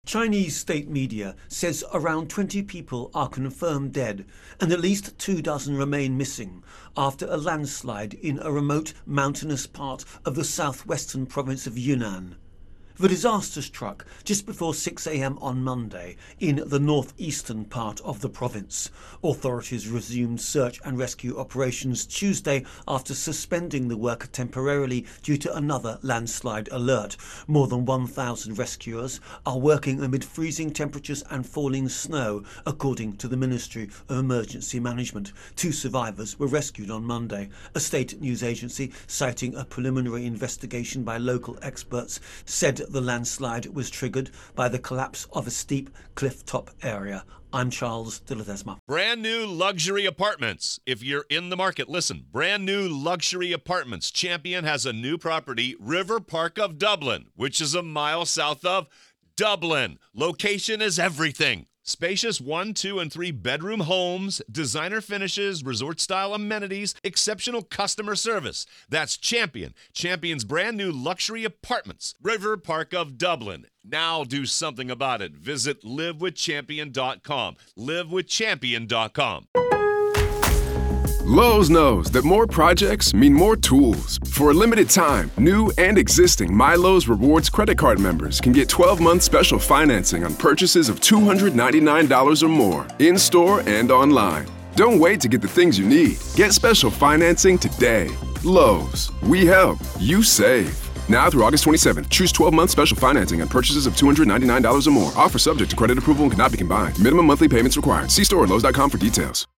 reports on China Landslide